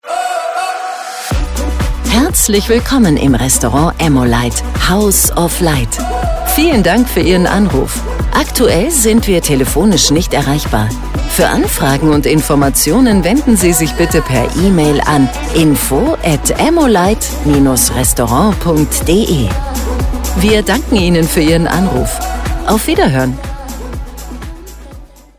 Telefonansagen mit echten Stimmen – keine KI !!!
Um so mehr, freuen wir uns, dass wir gerade die neuen Telefonansagen in 3 Sprachen für die neue Telefonanlage produzieren durften.